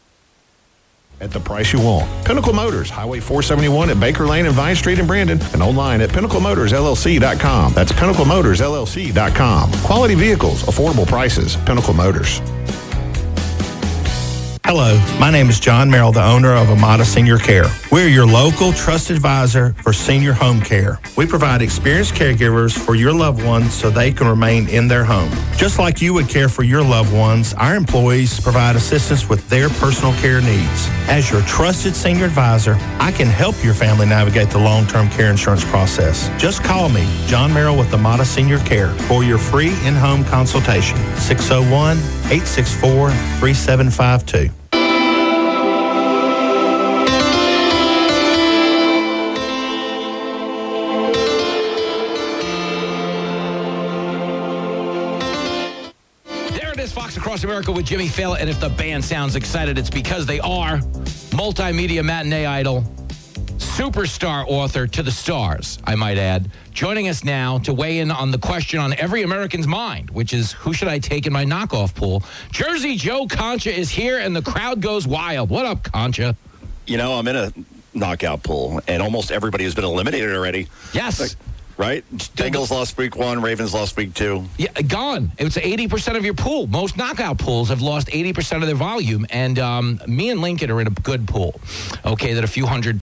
Here is another recording I made on September 22 from WFMN (Supertalk Mississippi)
That sounds like a typical super-compressed FM broadcast. No glitches, no gating, full spectrum.
It was the Sangean HDR-14 plugged into my computer through the aux cord.